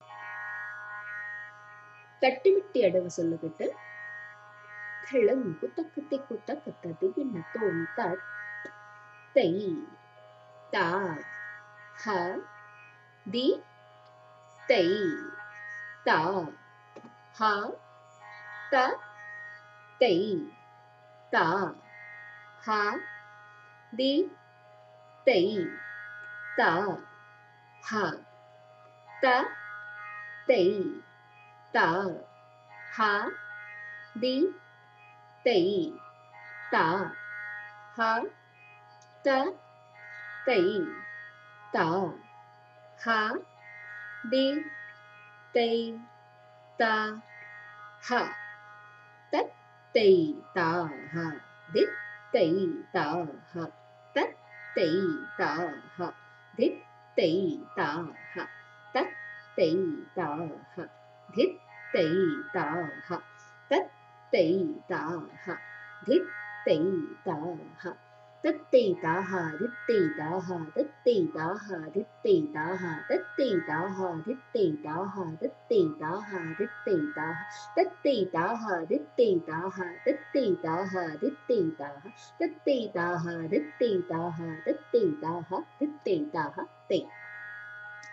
The Bols are ” Tat Tai Ta Ha, Dhit Tai Ta Ha“. It is sung in three speed for the purpose of practice.